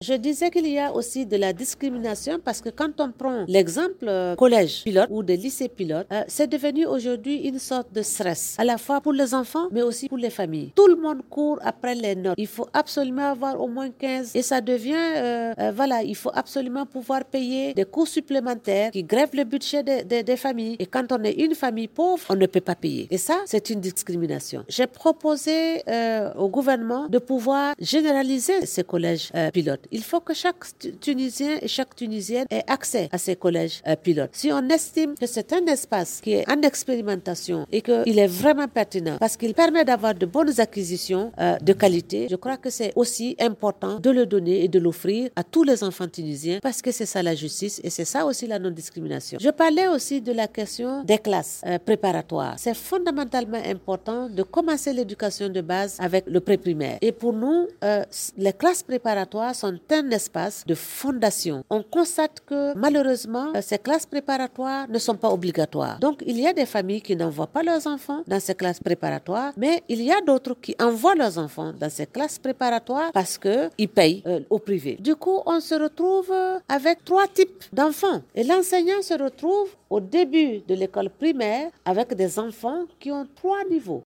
أكدت المقررة الخاصة بالحق في التعليم بالأمم المتحدة كومبو بولي باري في تصريح لمراسل الجوهرة "اف ام" اثر ندوة صحفية عقدتها اليوم بالعاصمة أن النظام التعليمي في تونس يكرس التمييز بين التلاميذ .